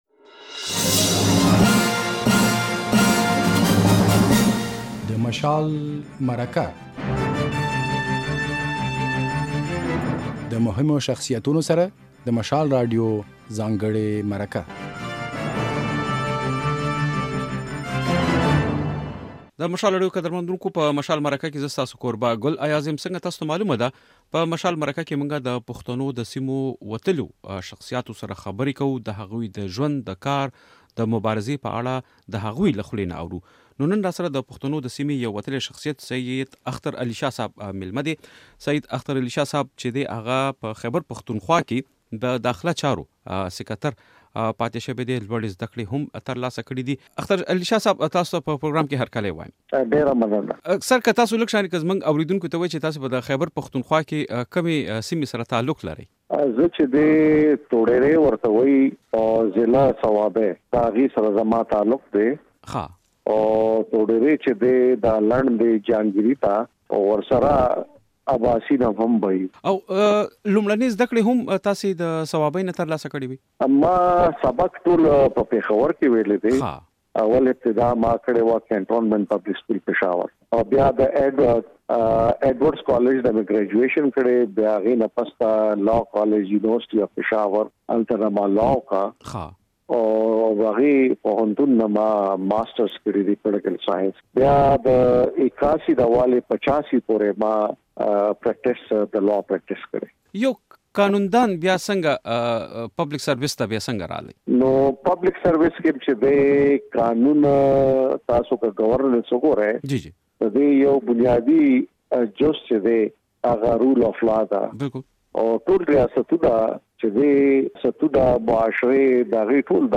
په مشال مرکه کې مو د خیبر پښتونخوا د کورنیو چارو له پخواني سېکرټر سید اختر علي شاه سره خبرې کړې دي.
د خیبر پښتونخوا امنیتي حالاتو په اړه بشپړه مرکه د غږ په ځای کې واورئ.